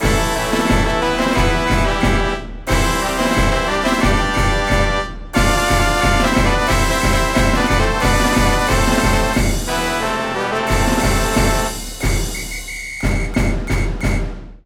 FIGHT SONG